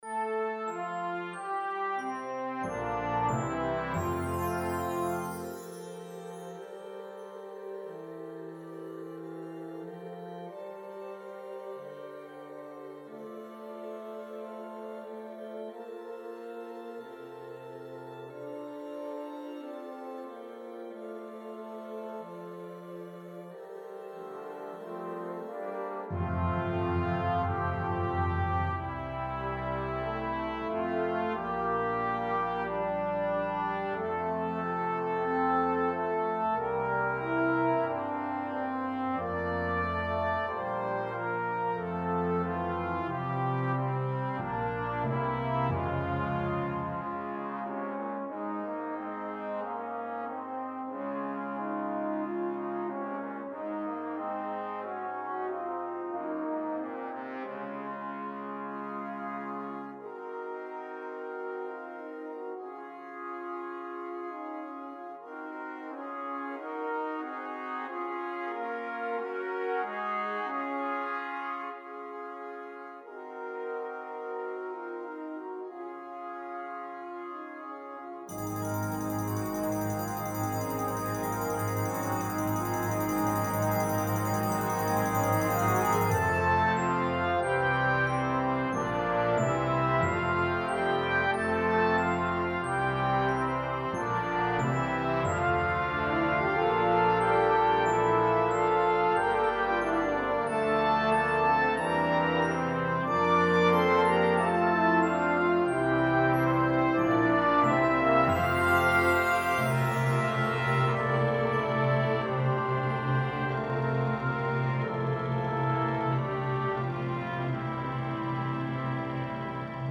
Besetzung: Euphonium Solo & Brass Band